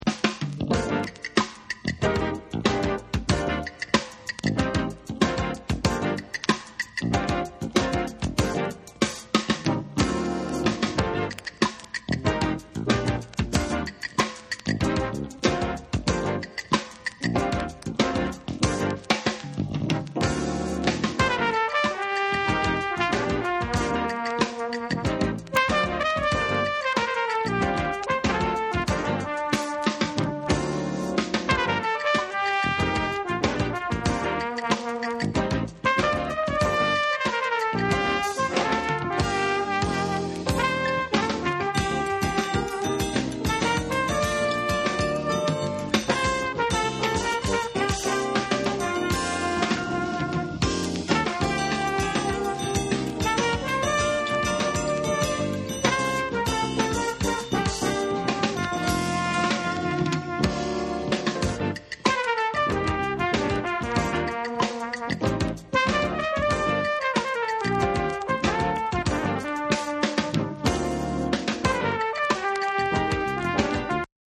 SOUL & FUNK & JAZZ & etc